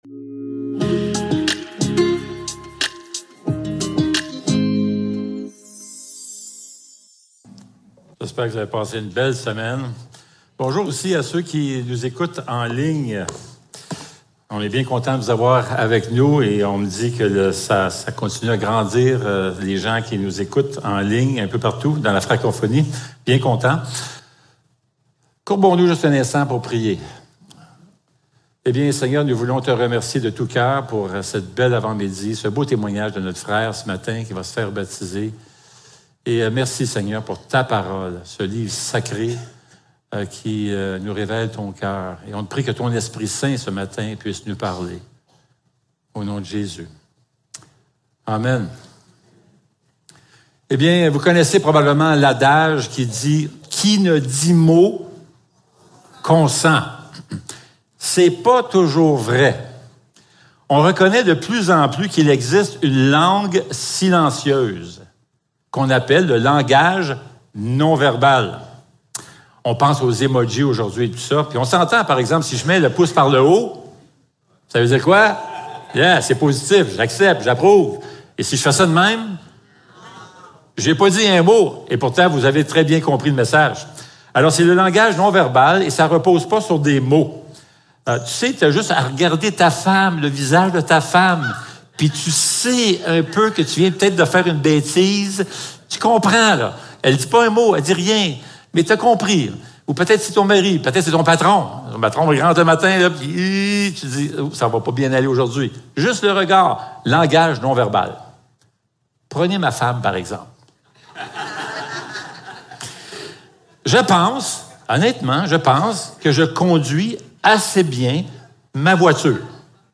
Psaume 19 Service Type: Célébration dimanche matin Description